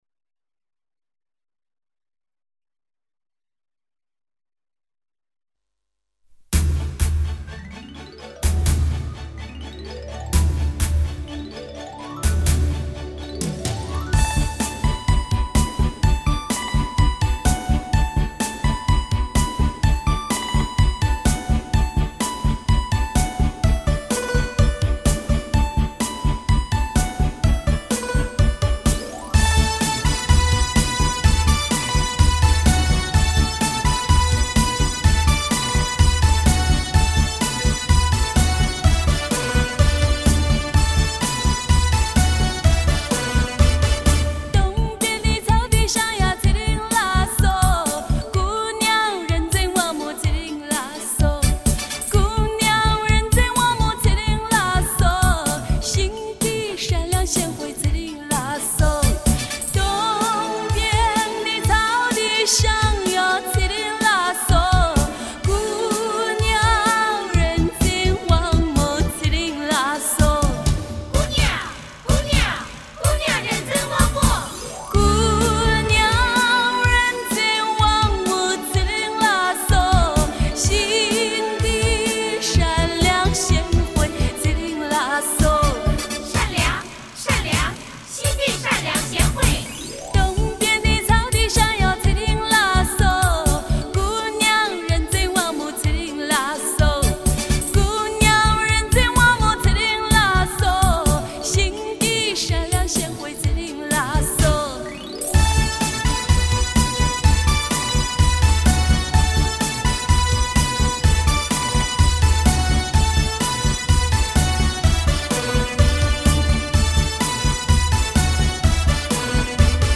其演唱风格流畅，自然,既有浓郁的民族特色又充满时代气息，她说：我要把反映藏族人民新生活的通俗歌曲唱遍全国。